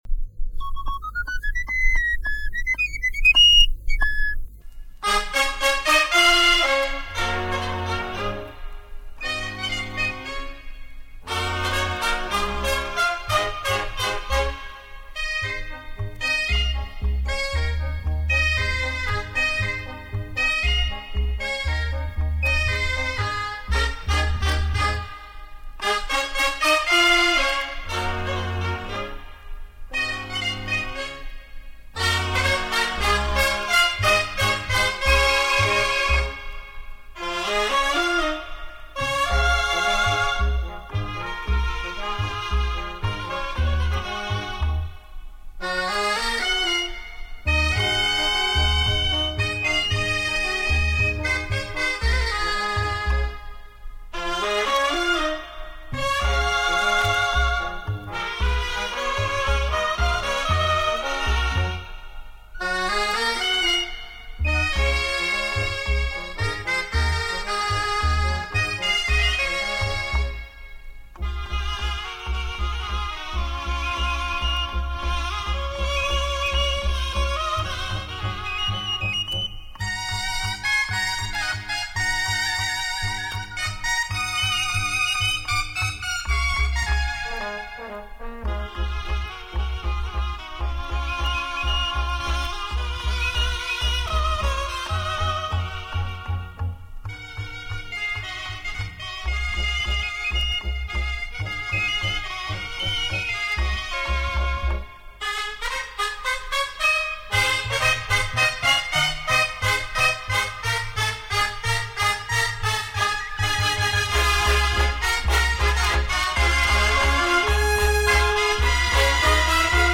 Sardana